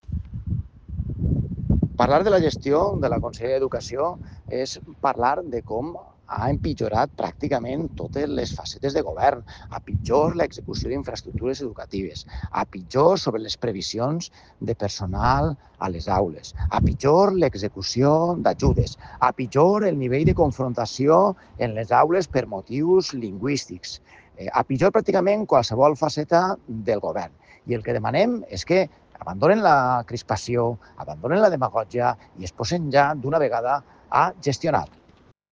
El portaveu d’Educació de Compromís a Les Corts, Gerard Fullana, ha valorat les xifres d’inici de curs escolar oferides pel conseller Rovira en Diputació Permanent de Les Corts.